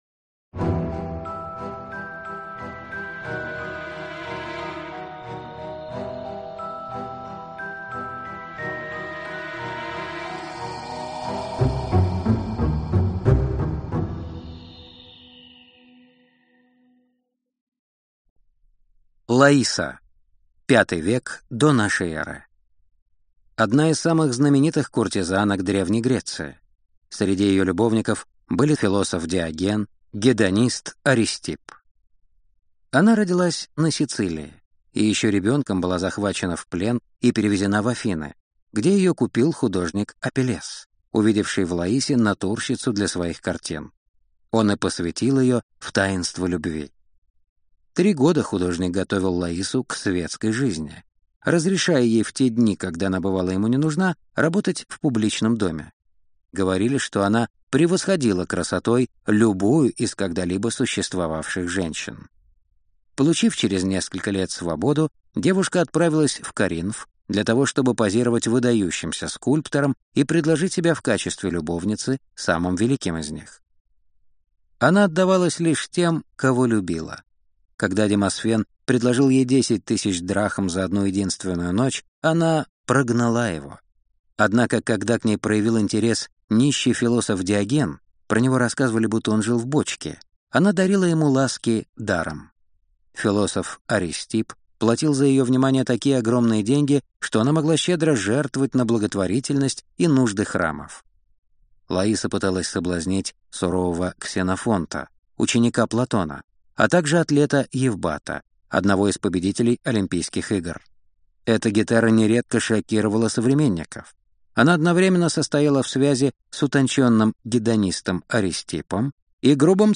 Аудиокнига Великие обольстительницы | Библиотека аудиокниг